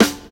• Subby Snare Single Hit D Key 57.wav
Royality free steel snare drum sample tuned to the D note. Loudest frequency: 1847Hz
subby-snare-single-hit-d-key-57-fhr.wav